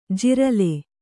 ♪ jirale